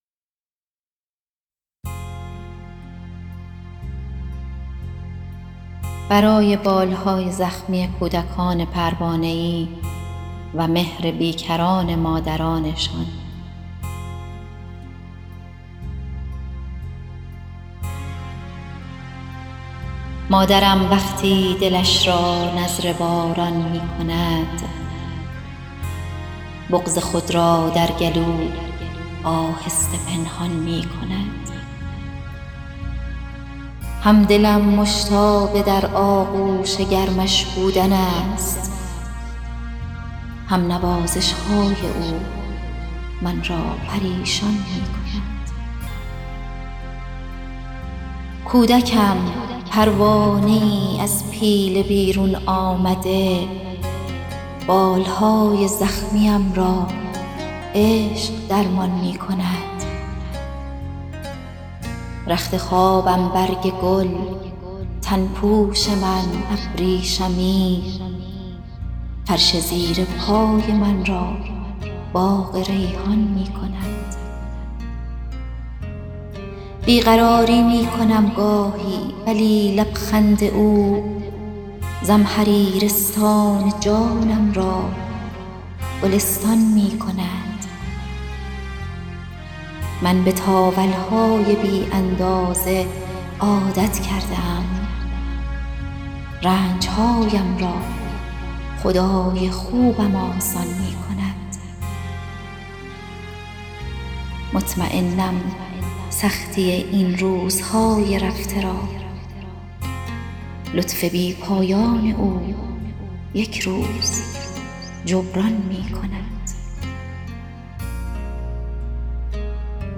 در ادامه برخی از اشعار قرائت شده در این محفلادبی را مرور می‌کنیم.